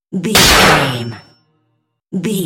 Dramatic hit deep metal clicnk
Sound Effects
heavy
intense
dark
aggressive
hits